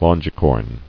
[lon·gi·corn]